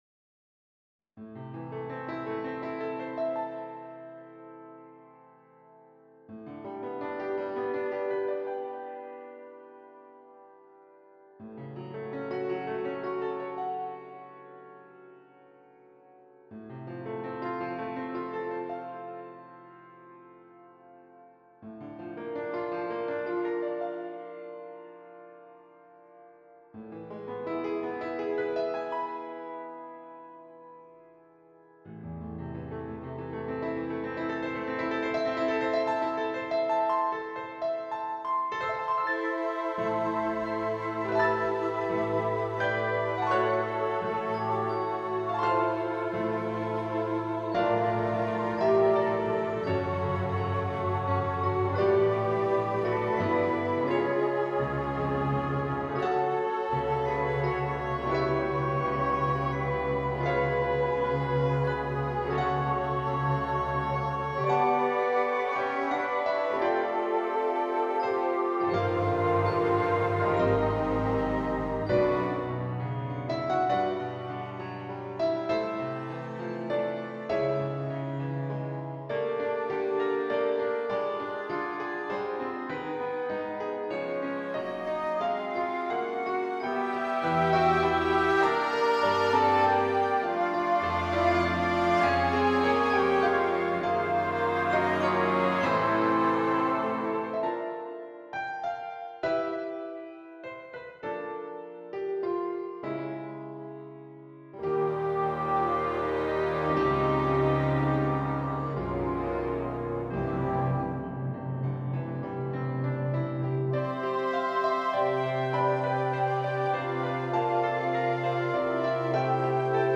Voicing: Piano and Concert Band